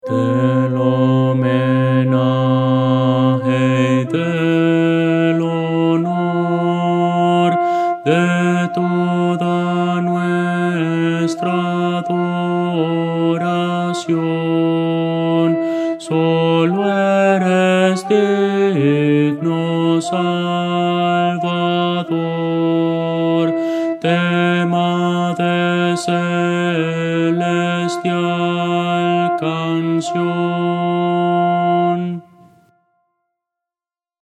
Voces para coro
Contralto – Descargar
Audio: MIDI